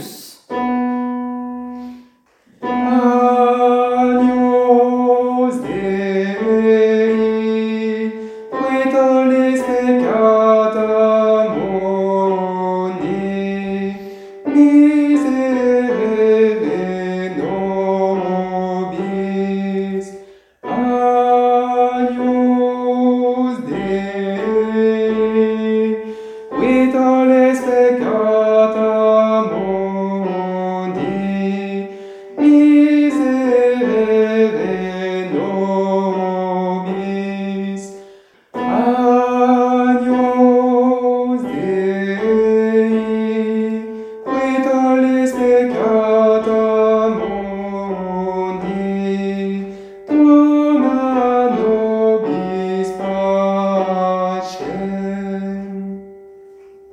Agnus messe de saint Augustin Polyphonies et voix disponibles: Agnus Messe de saint Augustin M Agnus dei messe de St Augustin T Partition(s): Voir Agnus Messe de saint Augustin Cette partition est protégée, veuillez vous connecter.